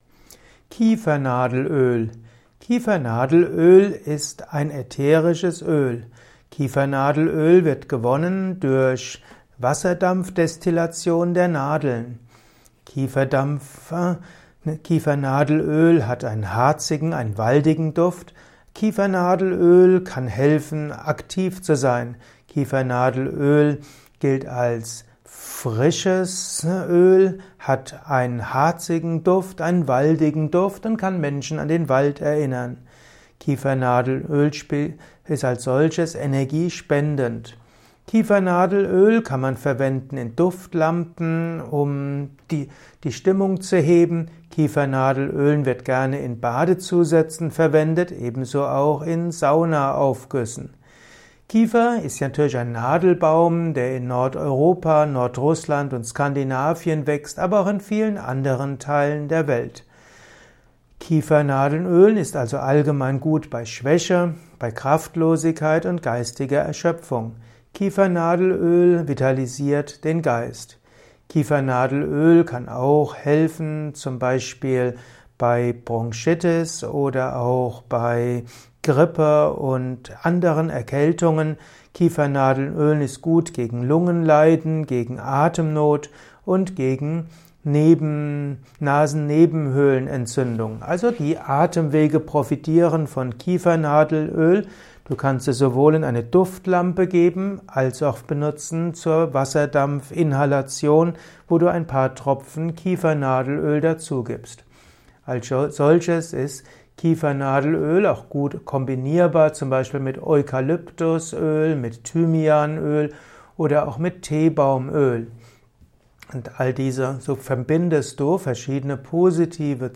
Vortragsaudio rund um das Thema Kiefernadel-Öl. Erfahre einiges zum Thema Kiefernadel-Öl in diesem kurzen Improvisations-Vortrag.